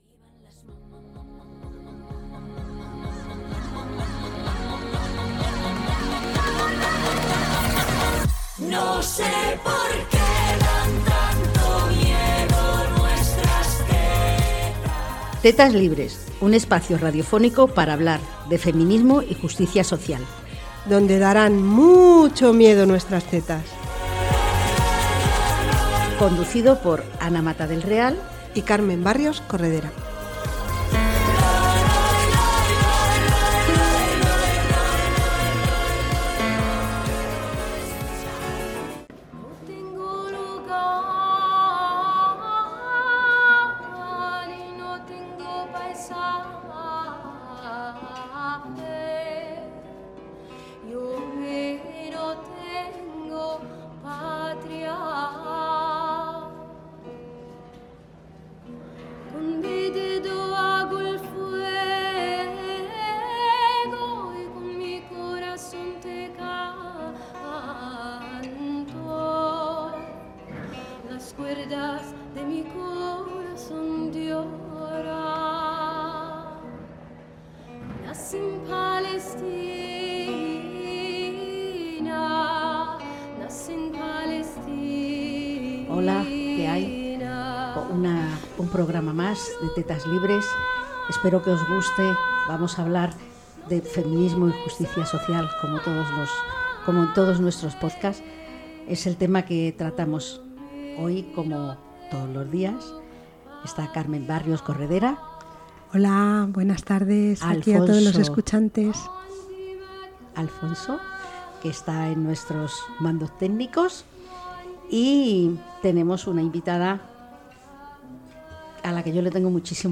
En esta nueva entrevista de Tetas Libres